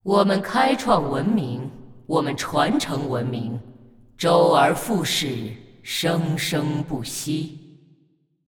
一个普通的音乐舒缓下心情
首先提示这个音频没有任何恐怖气氛，反而听起来很特殊。